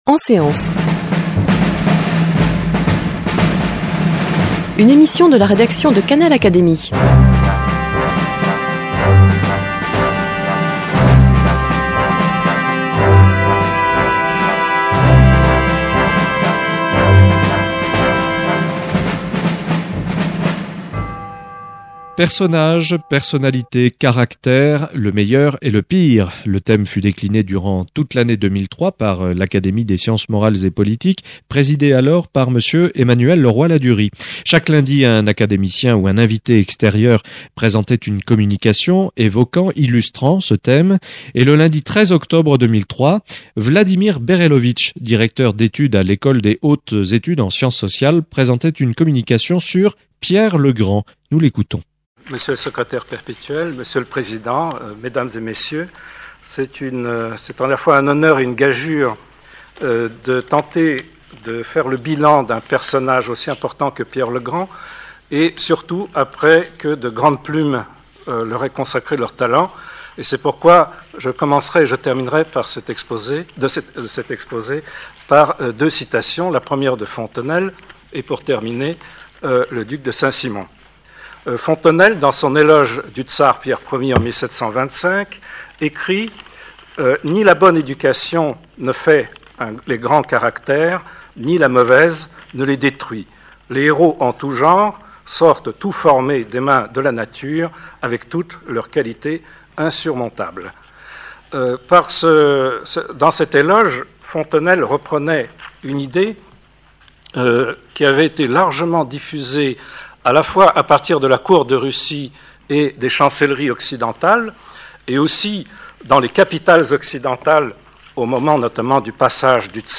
prononcée en séance publique devant l’Académie des sciences morales et politiques